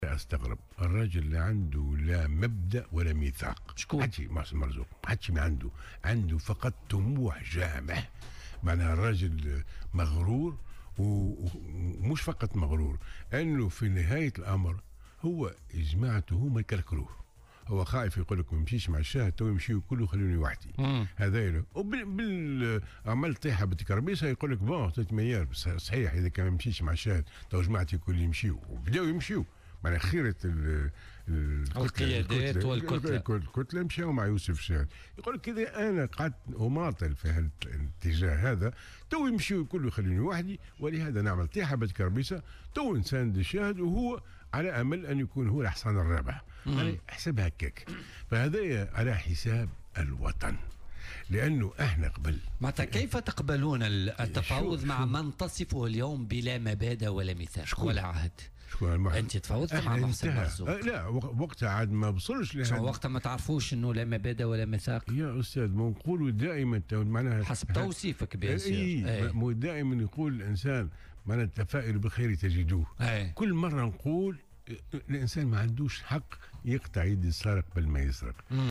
وقال بن حسين، ضيف برنامج "بوليتيكا" : لا أستغرب ذلك لأن مرزوق ليس له لا مبادئ ولا ميثاق وهو مغرور وله طموح جامح".وأضاف رئيس حزب المستقبل أن مرزوق خيّر الالتفاف حول رئيس الحكومة يوسف الشاهد.